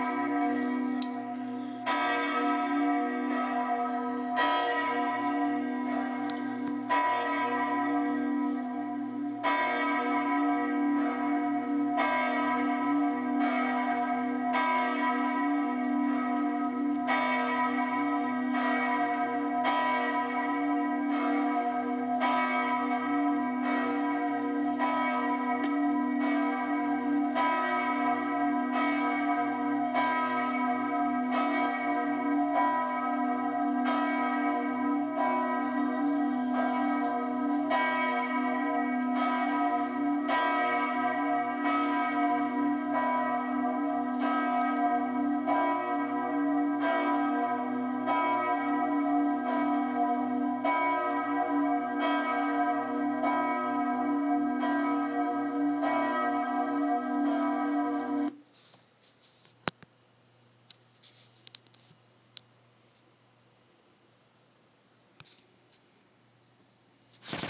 When you hear the bells of Chartres Cathedral [click here, recorded while visiting in 2012], their mystical energy vibrates within every cell of your body. I invite you to download the file and make it your ringtone so that your phone brings these resonant tones of peace to everyone in earshot, every time it rings.
chartres